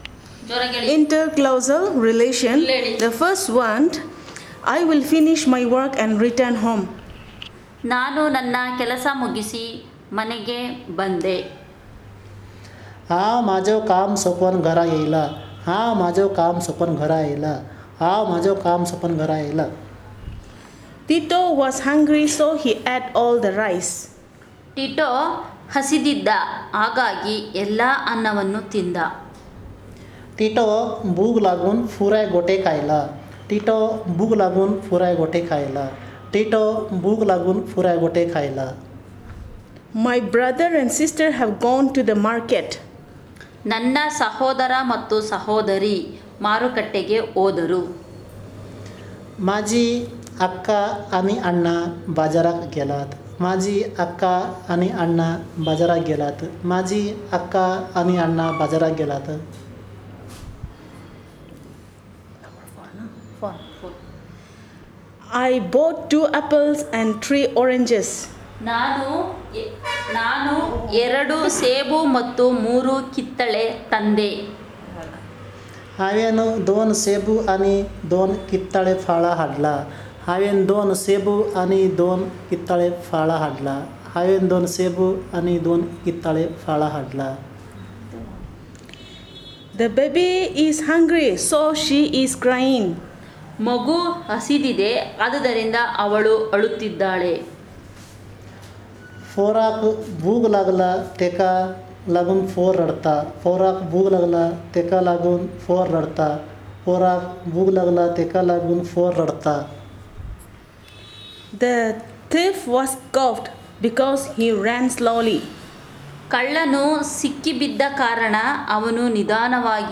Elicitation of sentences about interclausal relations